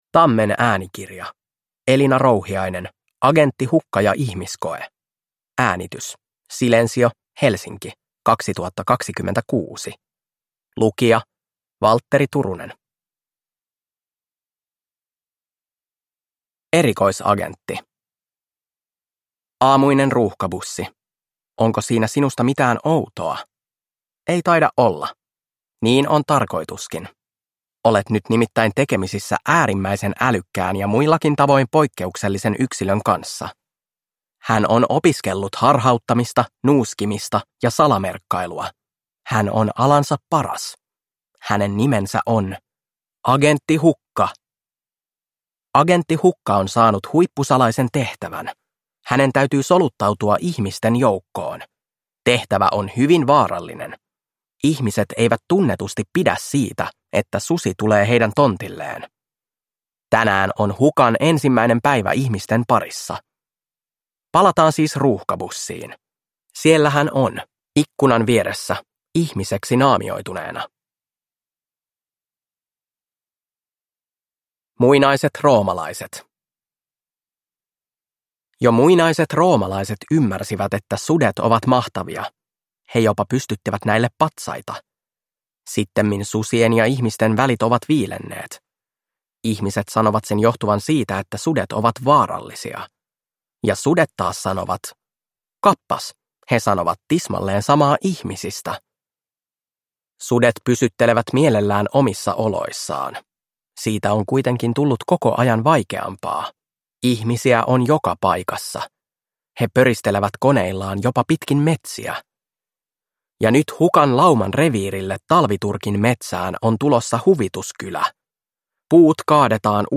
Agentti Hukka ja ihmiskoe – Ljudbok